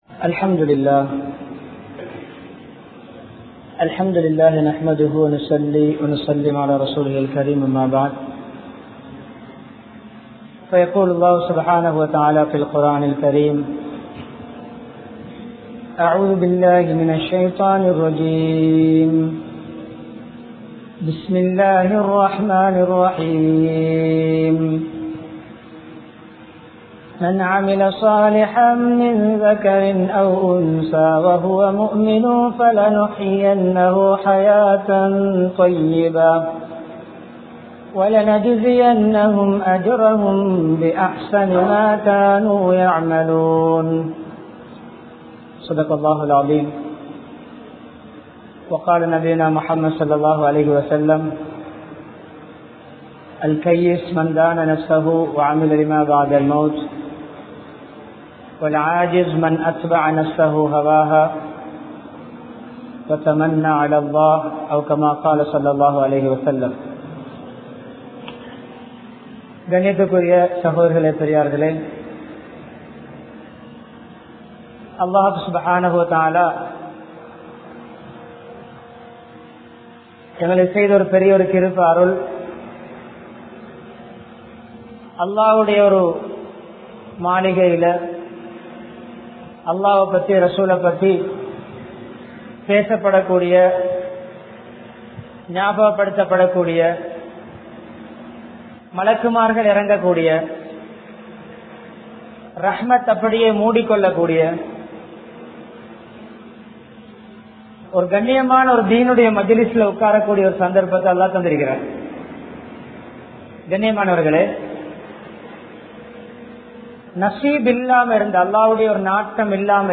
Marumaithaan Namathu Ilakku!(மறுமைதான் நமது இலக்கு!) | Audio Bayans | All Ceylon Muslim Youth Community | Addalaichenai
Colombo, Slave Island, Akbar Jumua Masjith